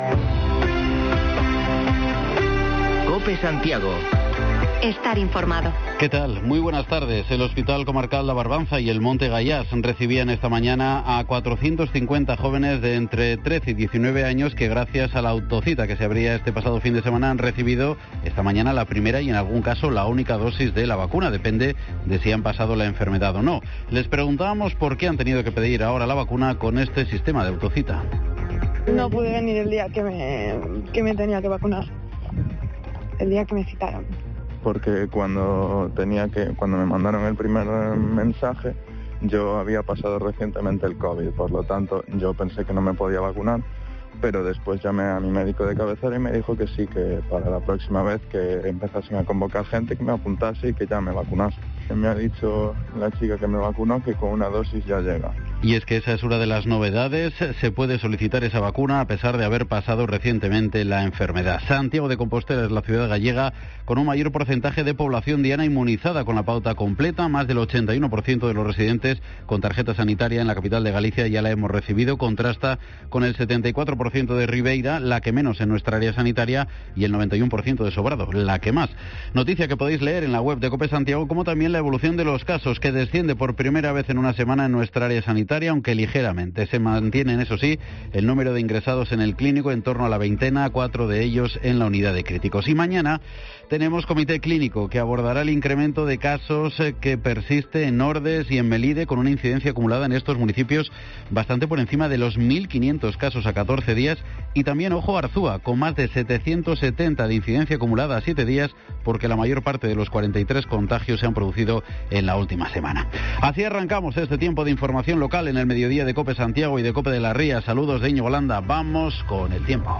Podcast: Informativo local Mediodía en Cope Santiago y de las Rías 30/08/2021